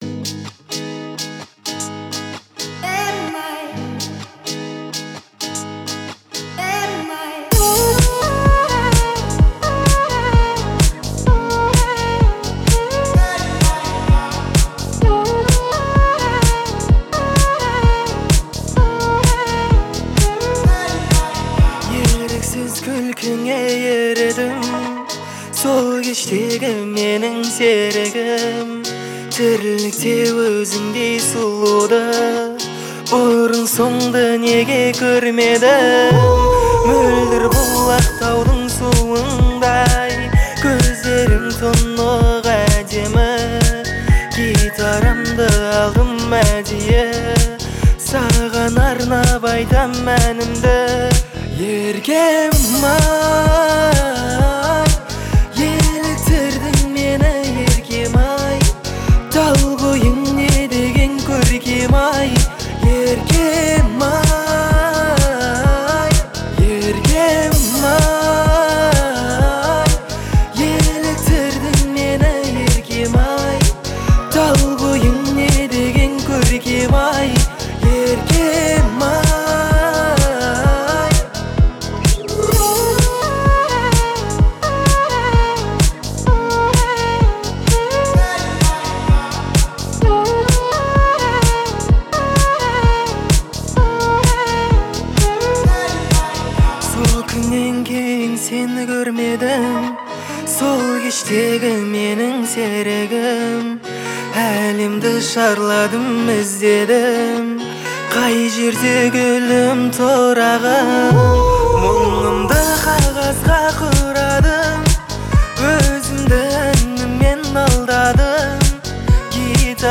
это вдохновляющая песня в жанре казахского попа